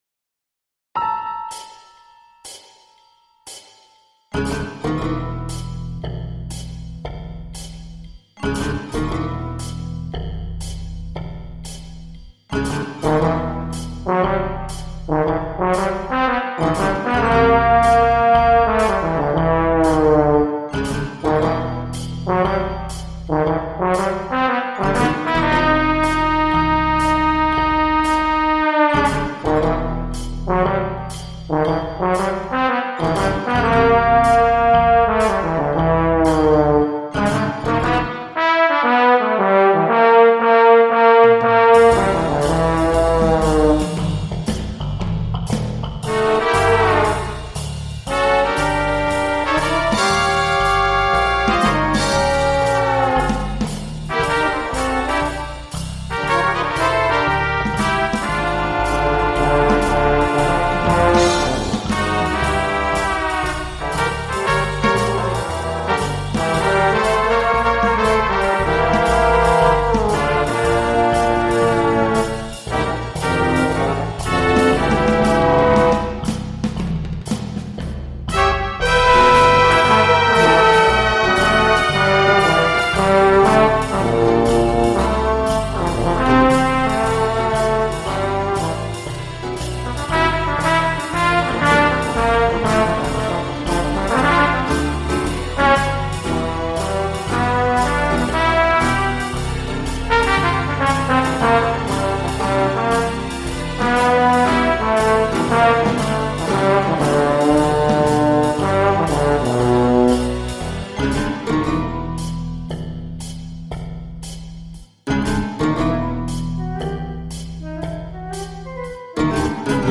cover arranged for Trumpets and Trombone
Default flute